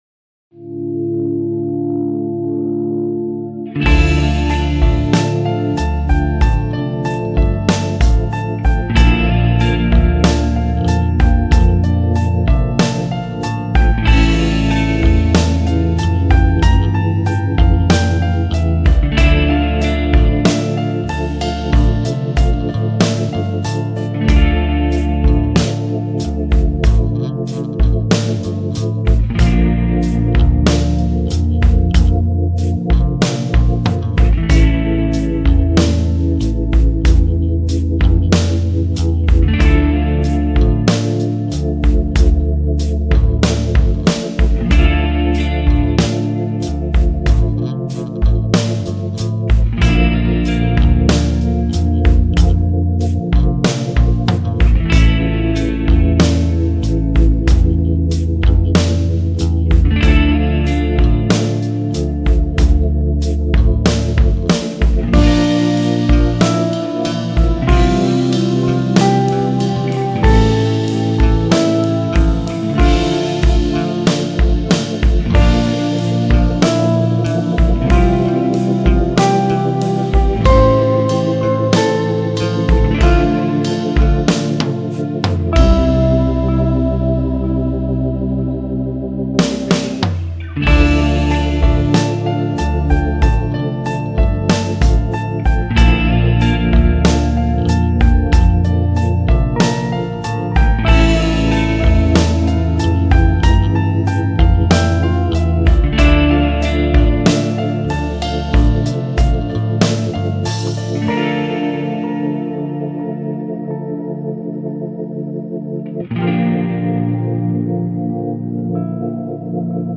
Eigentlich liegt sie sonst immer drüber. Für mich hört es sich nach akkustik Set Kick an :) Was meint ihr ?
(Drums noch zu laut)